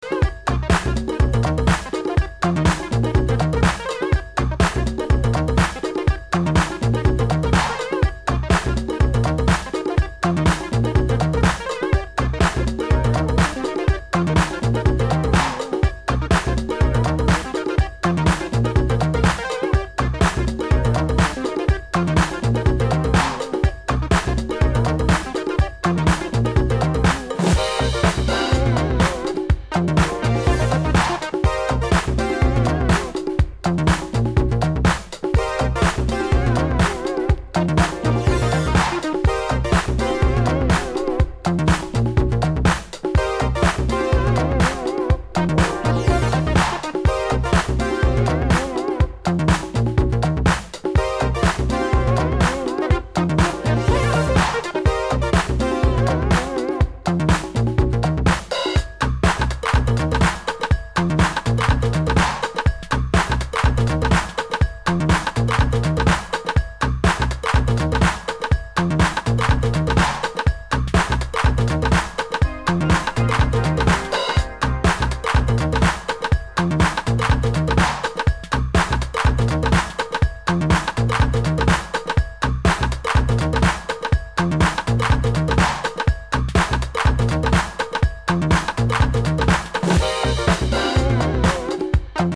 アナログ・ライクなシンセ・サウンドを前面に出した80'sテイストのブギー・ディスコ〜ファンクを披露！